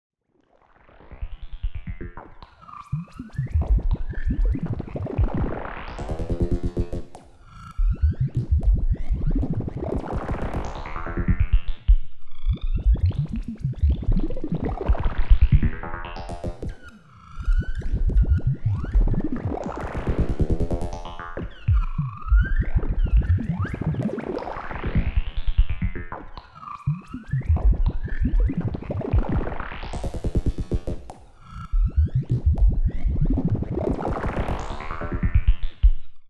all i got was hot bubbles tho… (but check out the kick drum right at the end!)